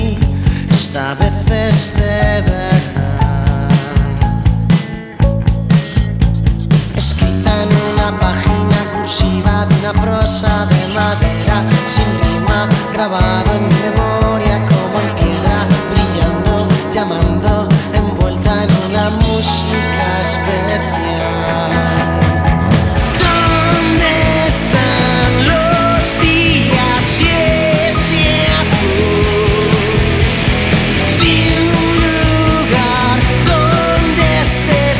Скачать припев
Baladas y Boleros Latin Pop Latino